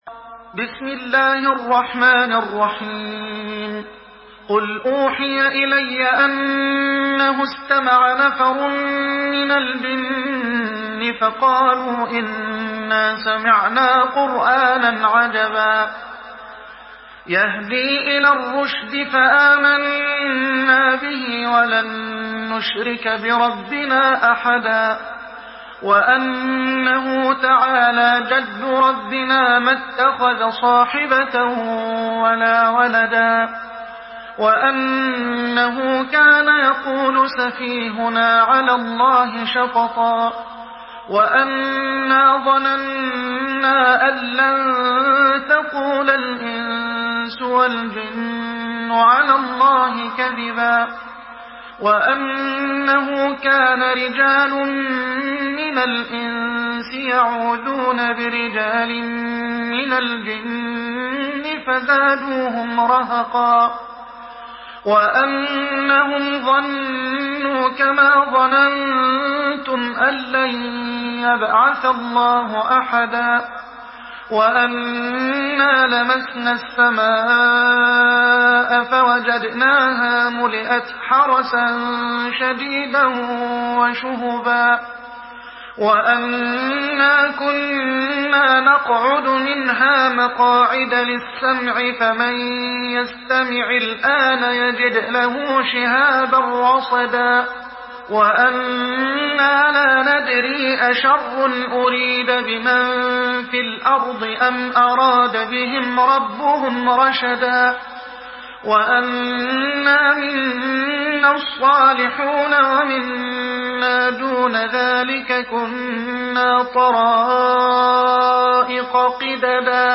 سورة الجن MP3 بصوت محمد حسان برواية حفص
مرتل